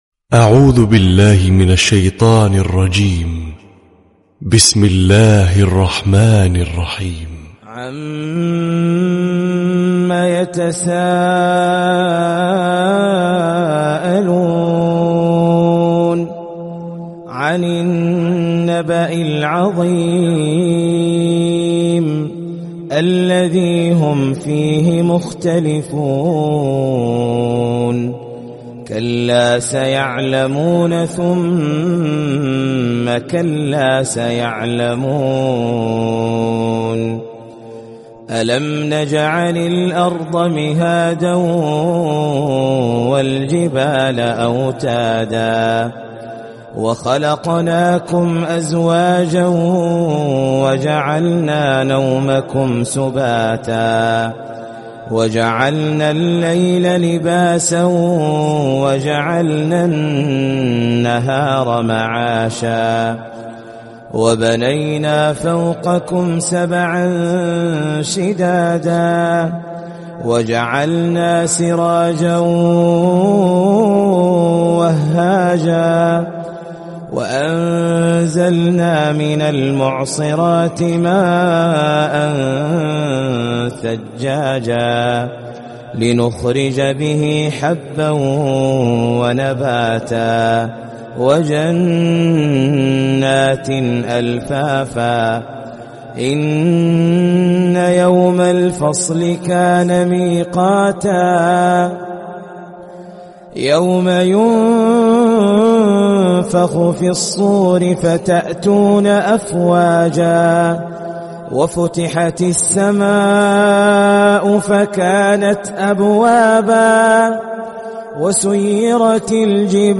🕋🌻•تلاوة صباحية•🌻🕋